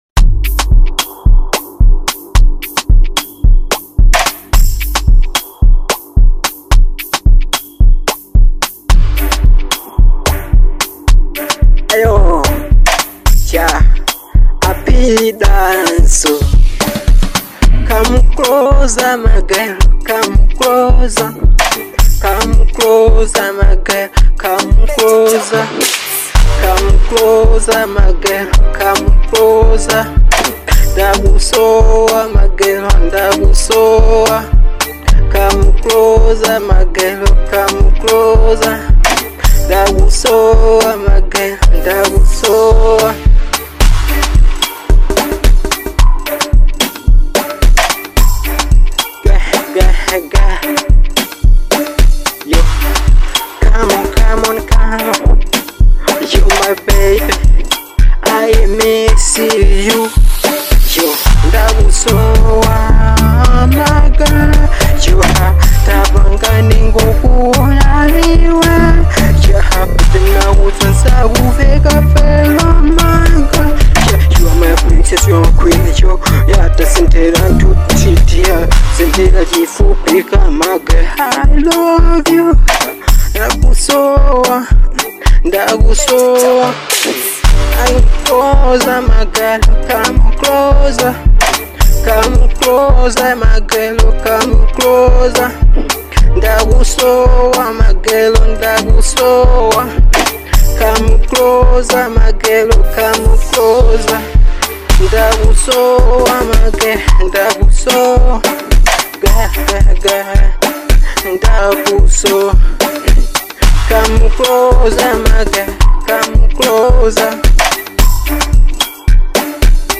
Genre : Afro-Pop